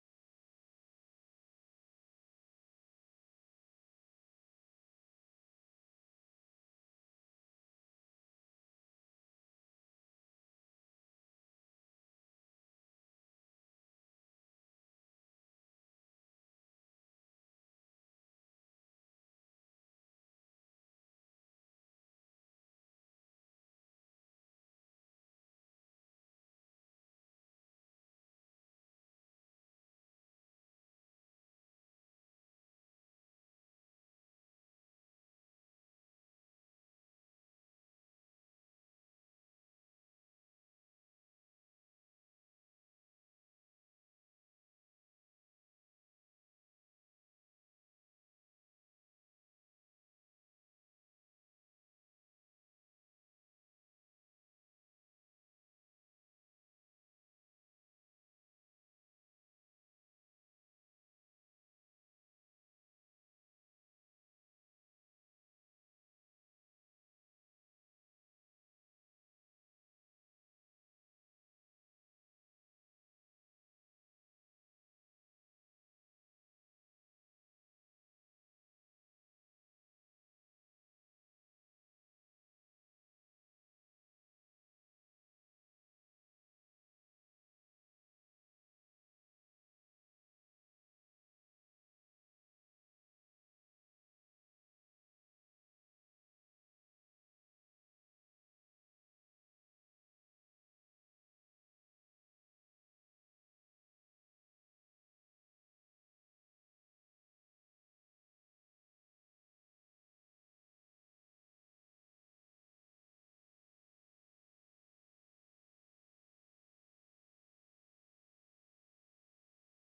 Scripture Reading: 1 Corinthians 11 Sermon: “1 Corinthians 11”